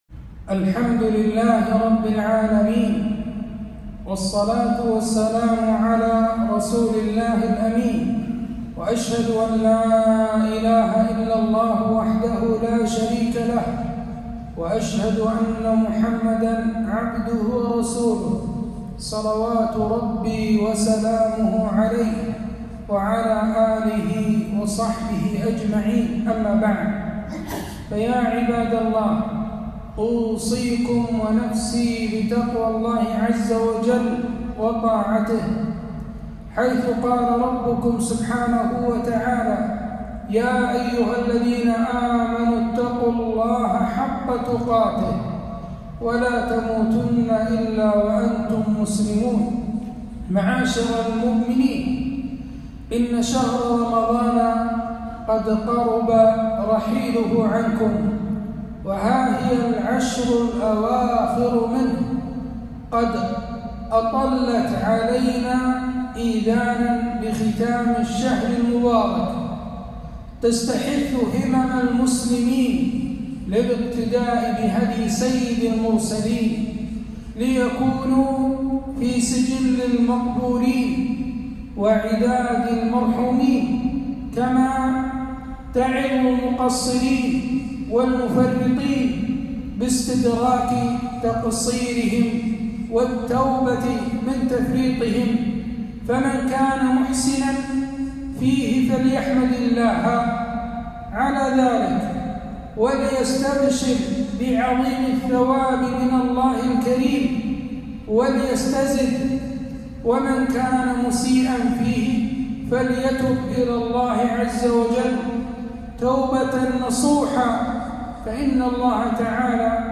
خطبة - هدي النبي ﷺ في العشر الأواخر من رمضان